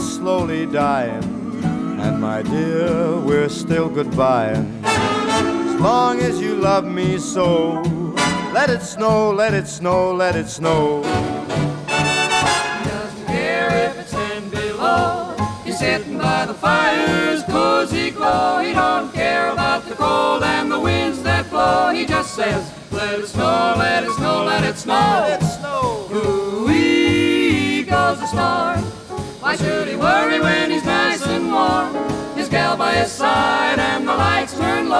# Christmas: Classic